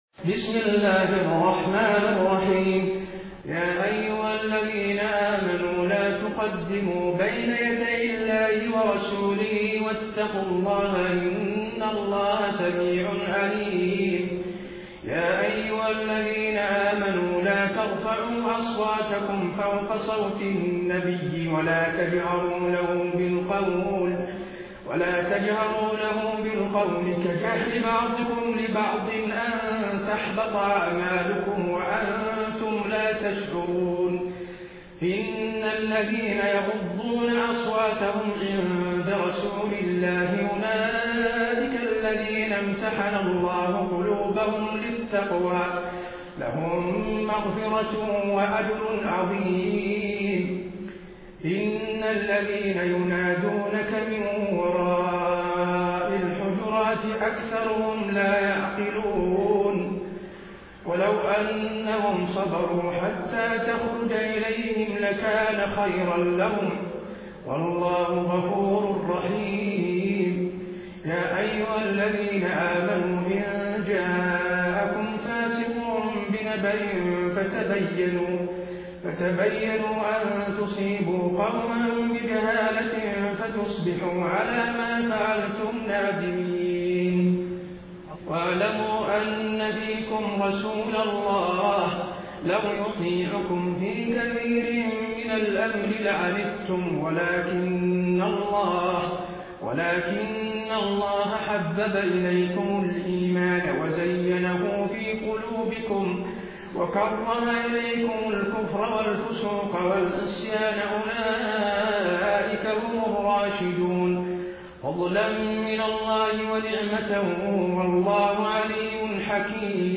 Recitations
taraweeh-1433-madina